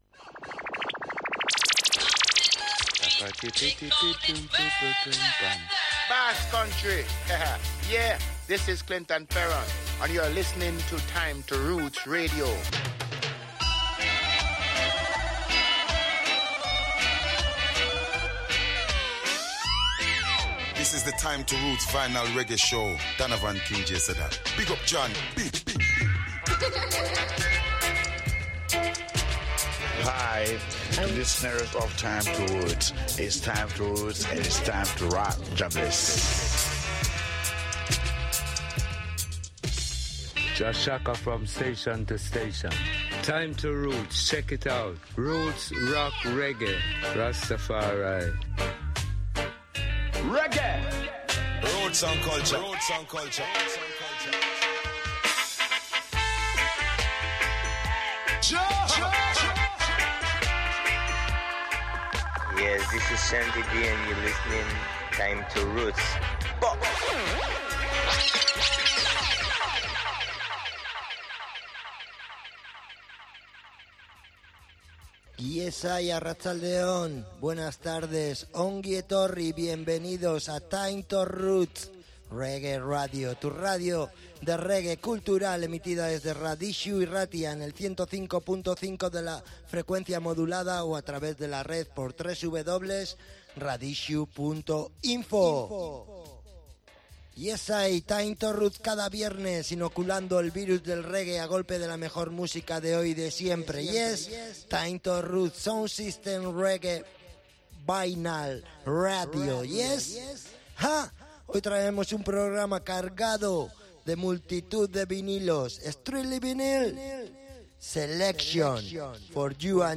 Analog Reggae Music in a Digital World.